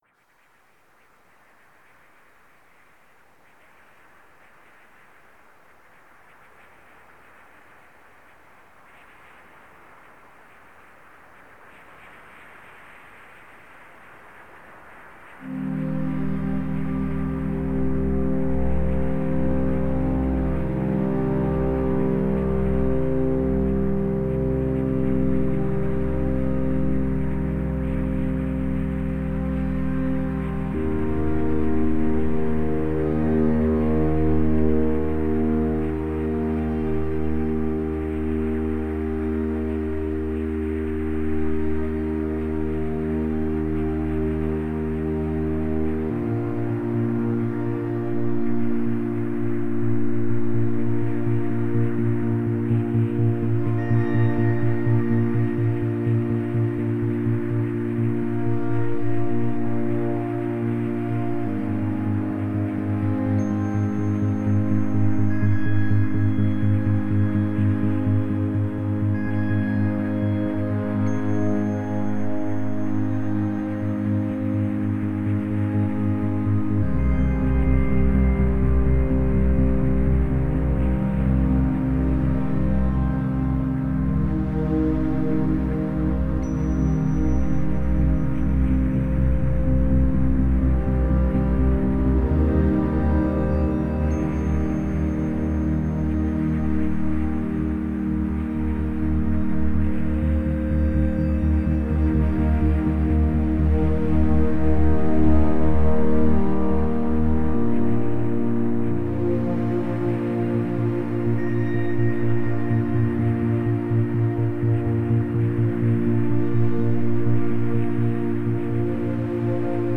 E.g. it is trivial to synthesize something like this completely on the Woovebox itself;
…but all is assembled from just white noise, sine, triangle, square and saw waves in real-time, without a sample in sight for this “song”.